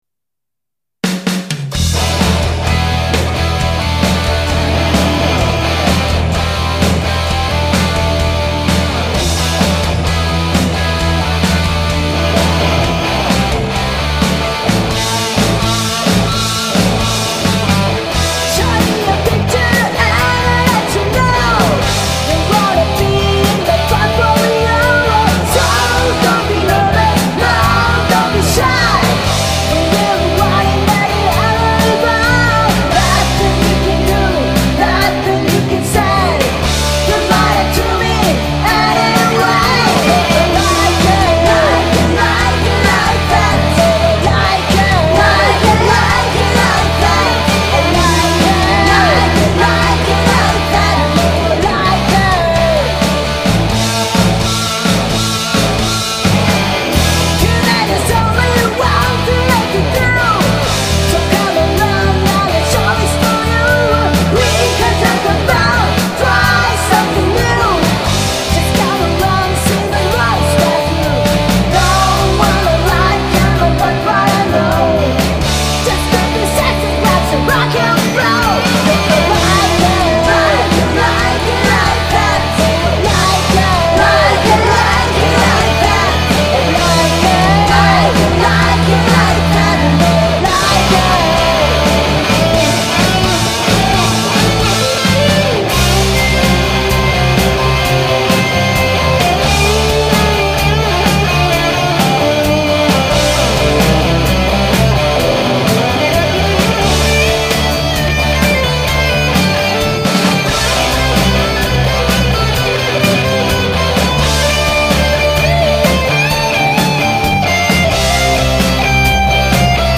多重録音元年で、宅録の年だったなぁ。
（↓VoとDrumsやってます）